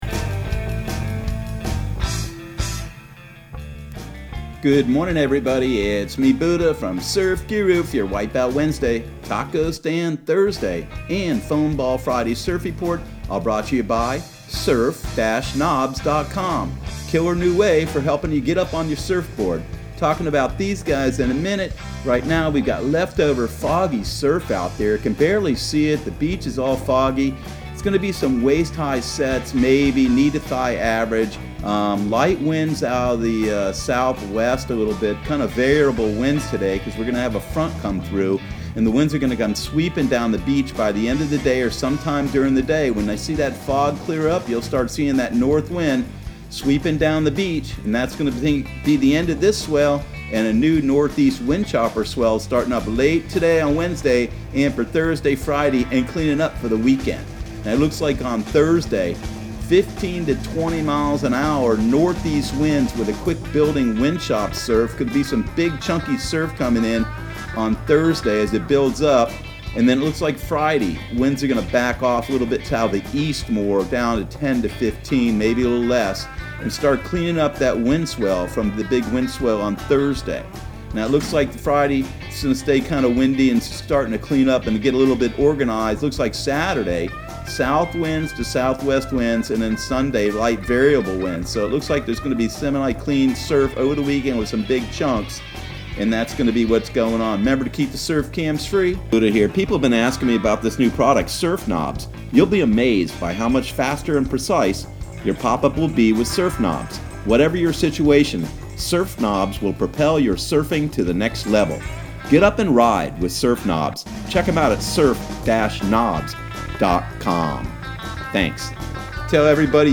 Surf Guru Surf Report and Forecast 12/11/2019 Audio surf report and surf forecast on December 11 for Central Florida and the Southeast.